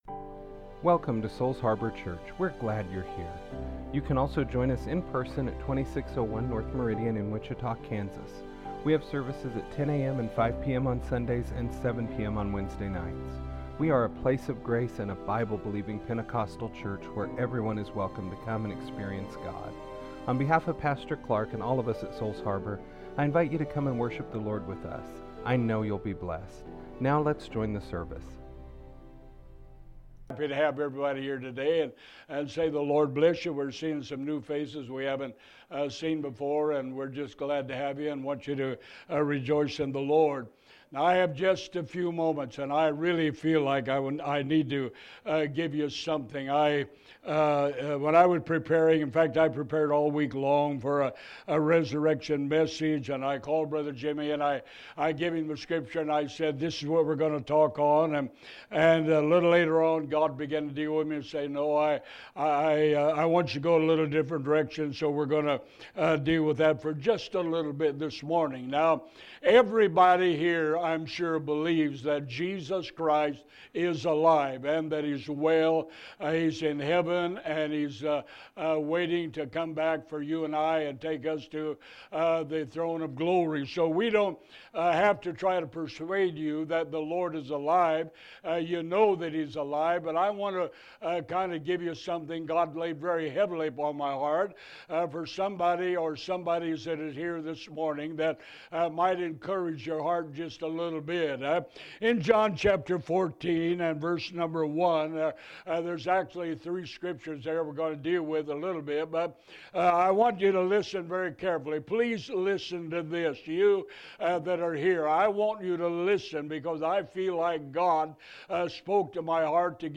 Join us for a message straight from the Pastor's heart for the people of God. Be blessed as we celebrate the resurrection of our King and Savior!